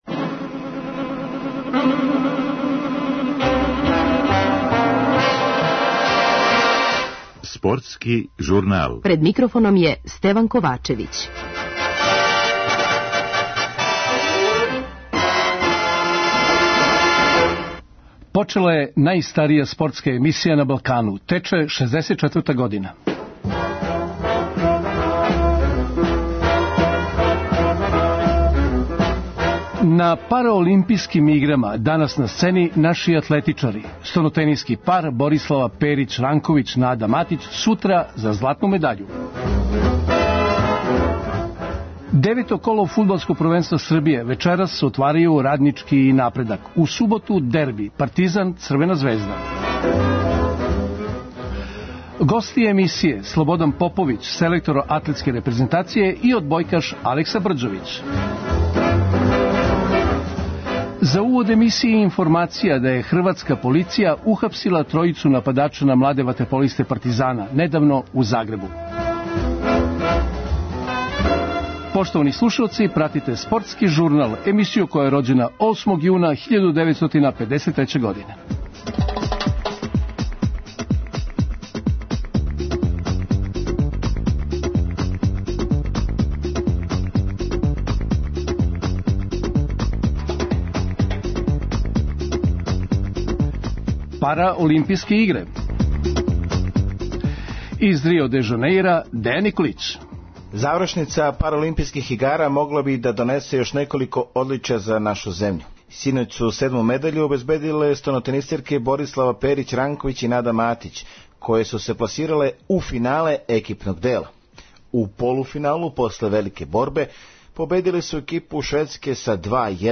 У Спортском журналу разговори и директна укључења из Рио де Жанеира, са Параолимпијских игара.